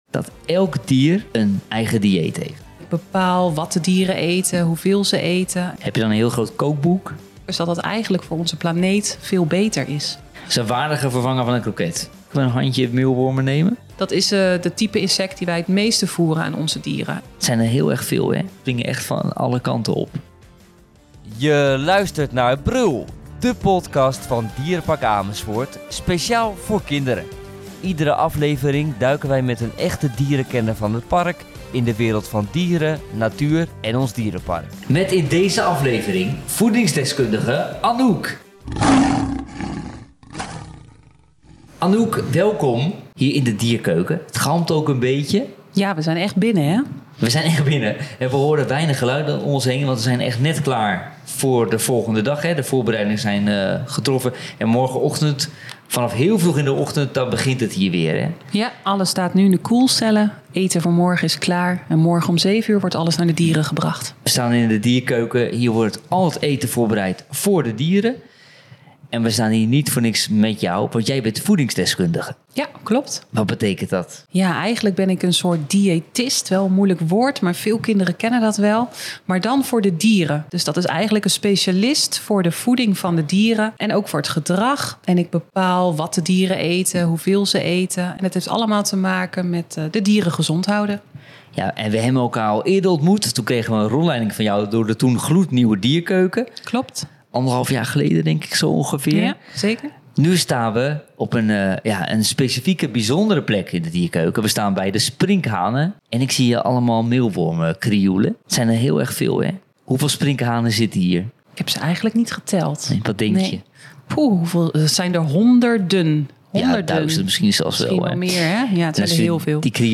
Als hobby lees ik deze verhalen voor.